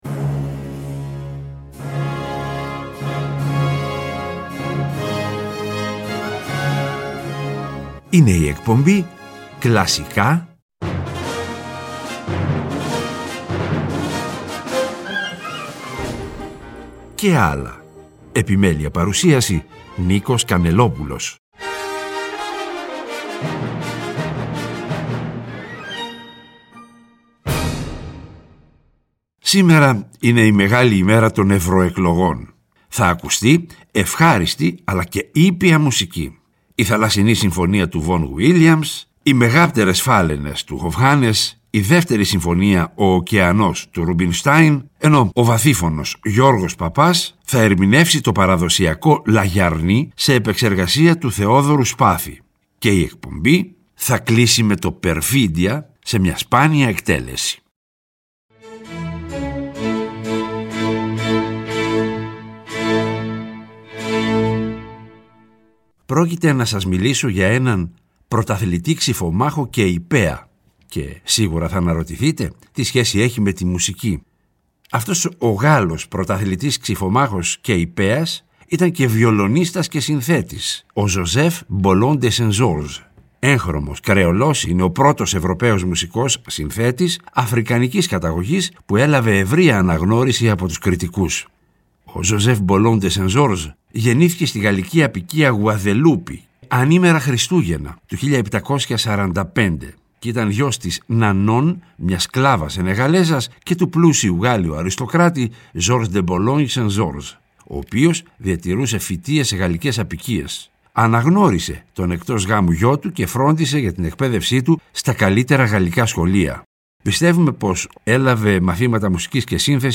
Ημέρα Κάλπης με Ευχάριστη και Ήπια Μουσική
βαθύφωνος